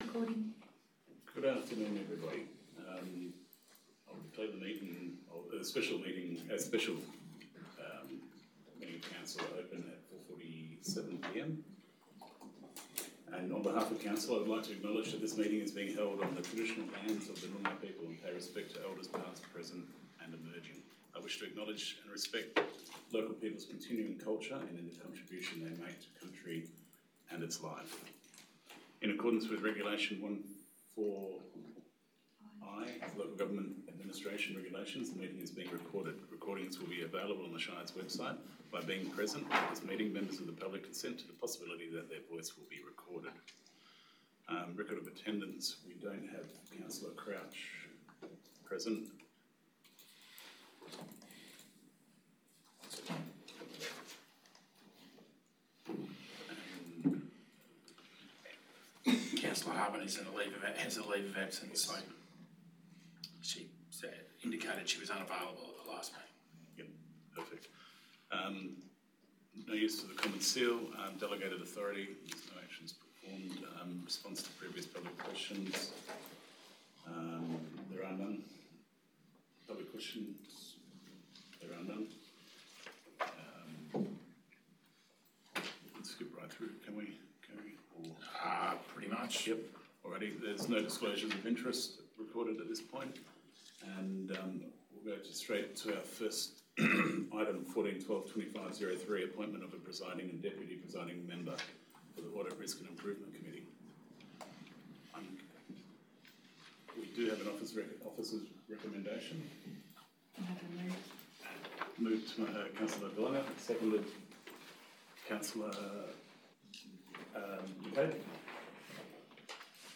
18 December 2025 - Special Meeting of Council » Shire of Brookton
special-council-meeting.mp3